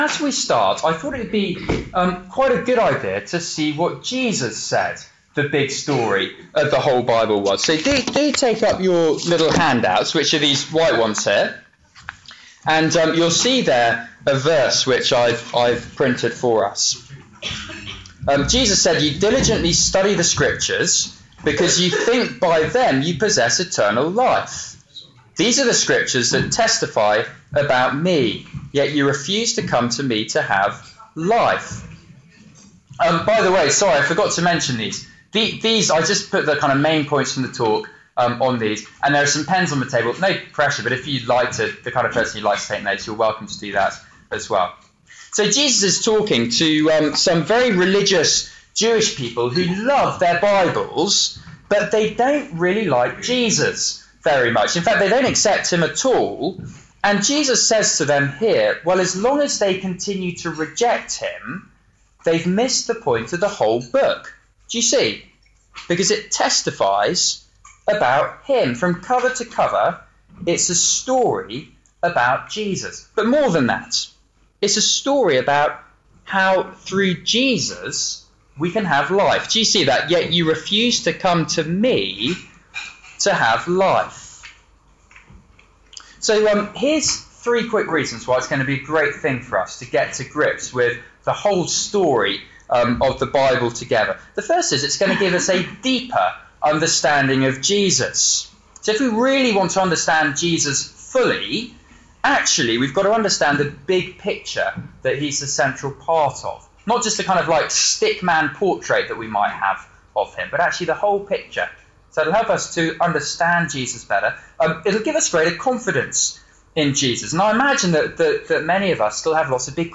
Media for Seminar